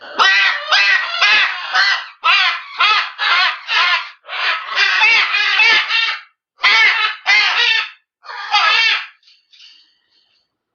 Chant d'oiseau gratuit - page 12
Chants et bruitages d'oiseaux